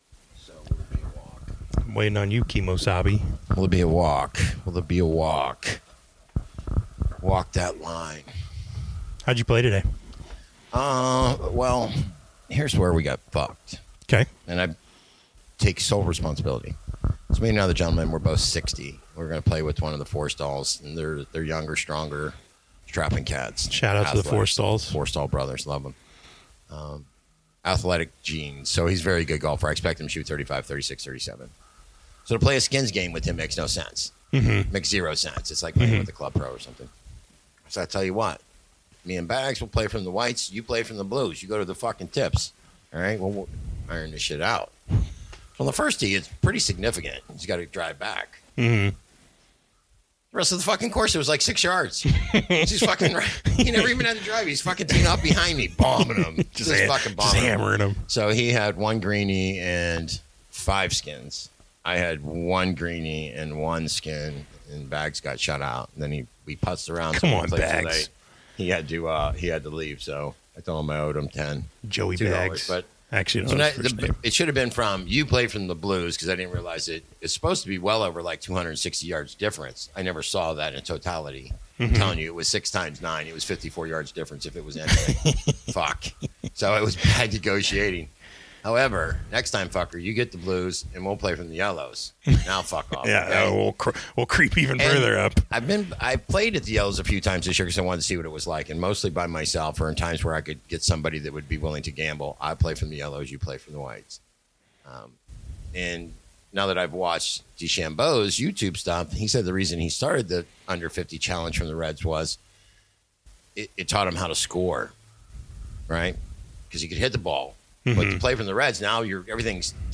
A father and son pair of degenerate gamblers decided to turn on some microphones to record their musings on the NFL season and how to navigate the sports betting landscape.